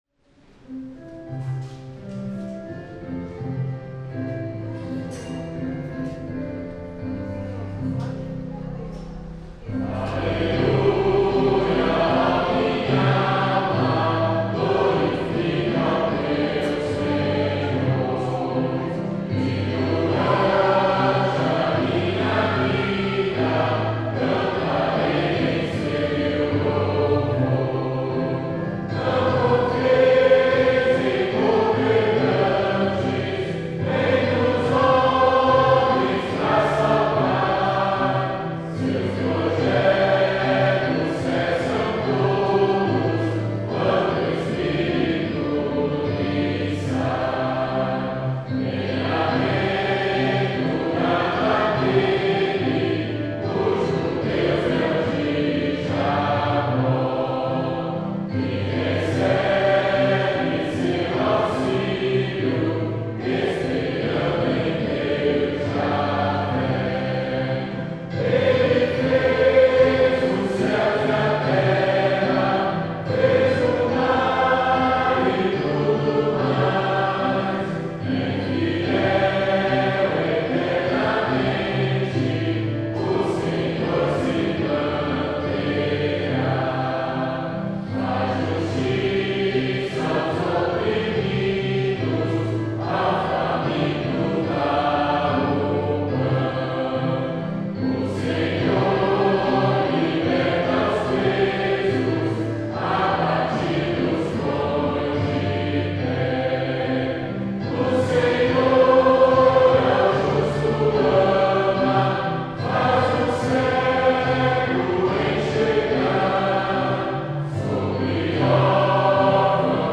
salmo_146B_cantado.mp3